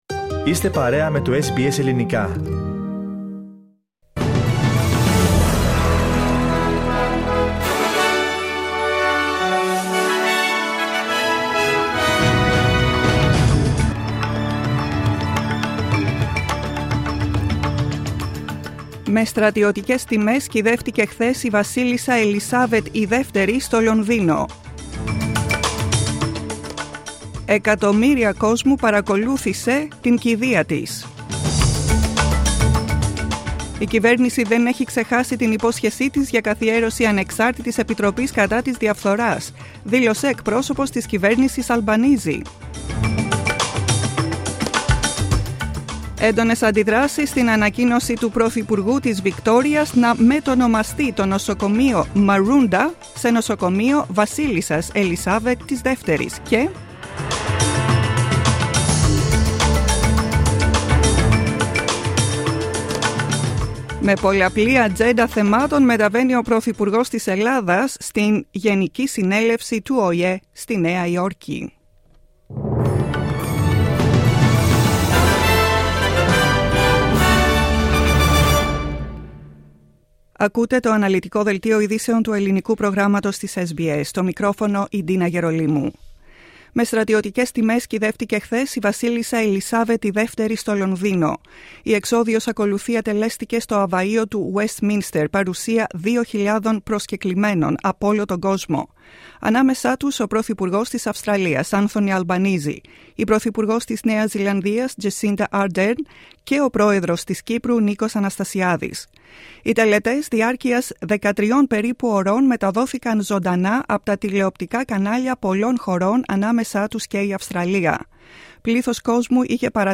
Listen to the main bulletin of the day from the Greek Program.